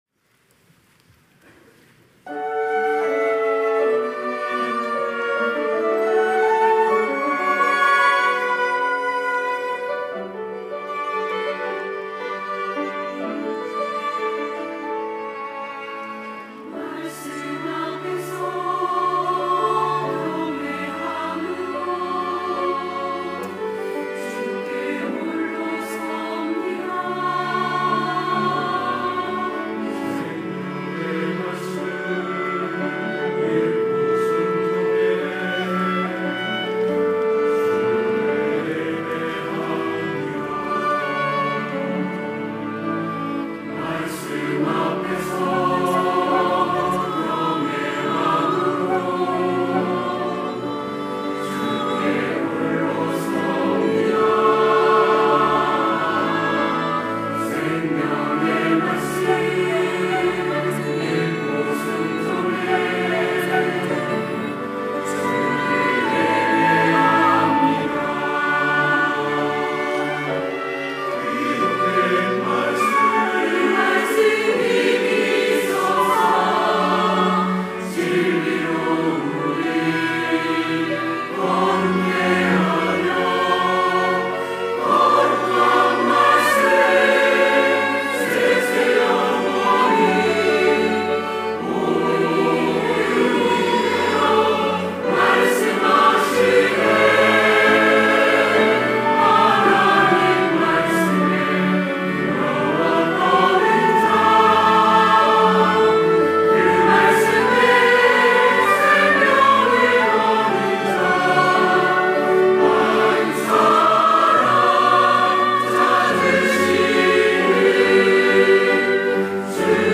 호산나(주일3부) - 말씀 앞에서
찬양대